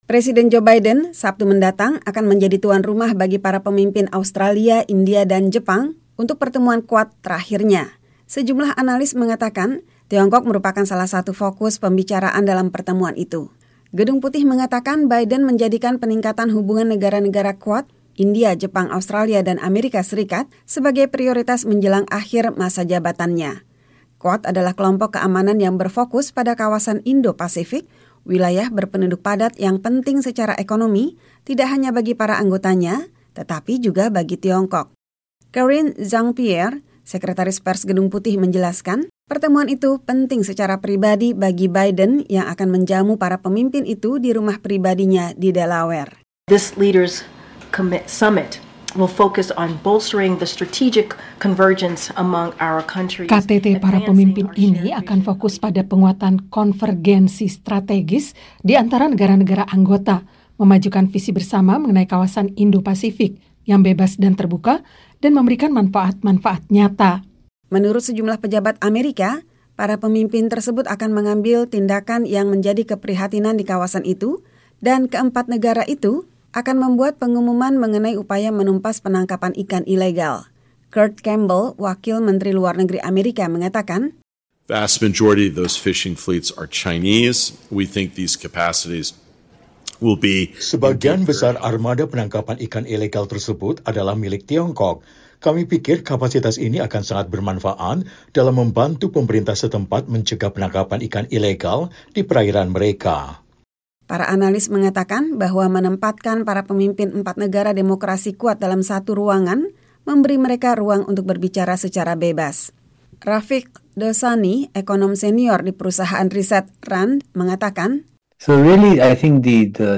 Sejumlah analis mengatakan, Tiongkok merupakan salah satu fokus pembicaraan dalam pertemuan itu. Tim VOA melaporkan.